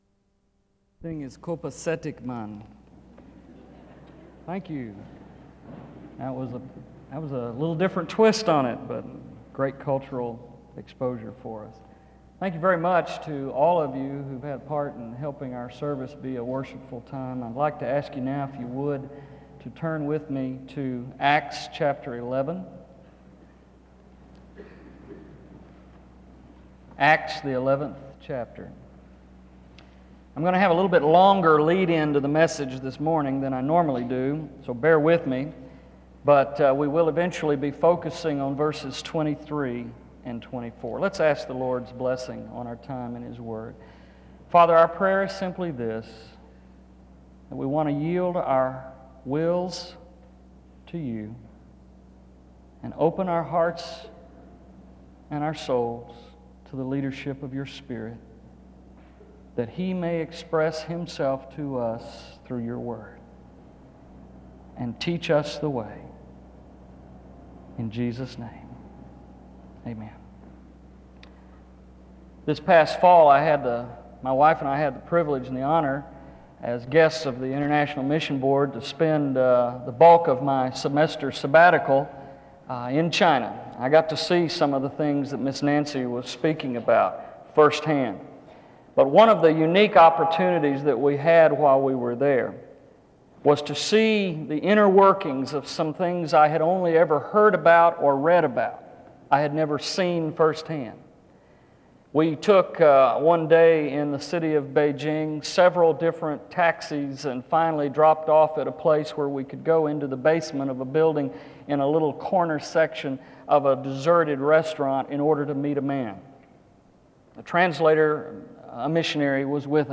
Wake Forest (N.C.)
SEBTS Chapel and Special Event Recordings - 2000s